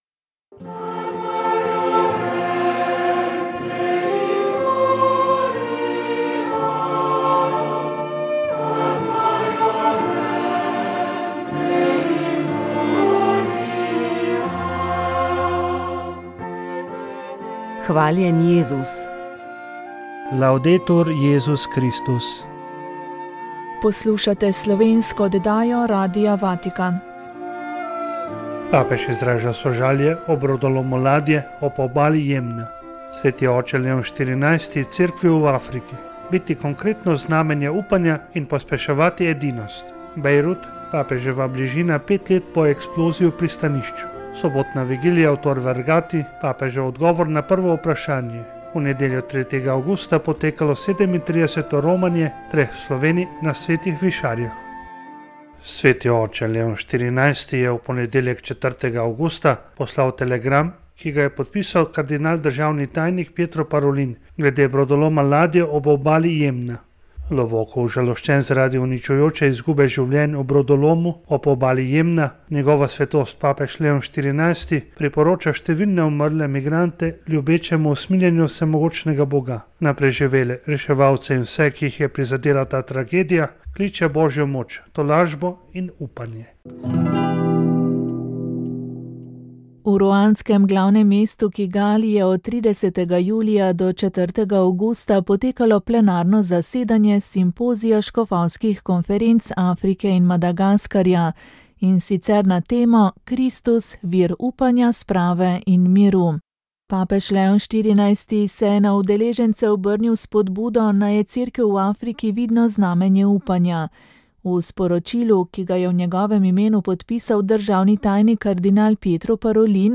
komentar politika družba evtanazija zakon o asistiranem samomoru